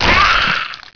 plagueElf
death3.wav